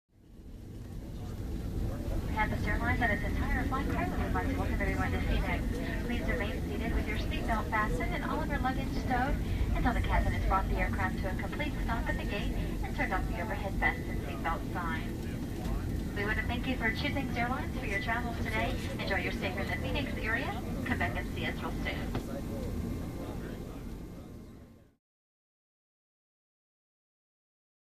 Female Flight Announcements Before Landing, With Light Passenger Walla